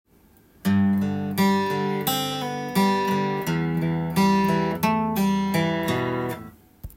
アルペジオ例
例の①～④すべて　ルート音からアルペジオが始まっています。
③がそのプリングのパターンです。